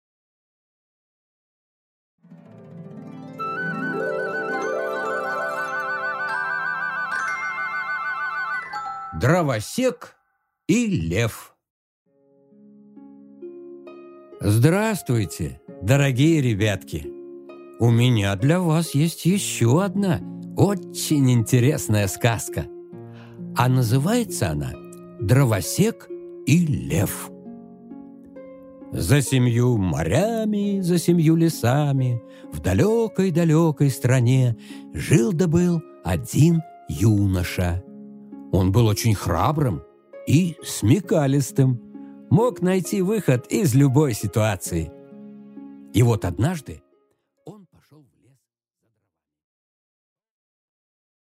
Аудиокнига Дровосек и лев | Библиотека аудиокниг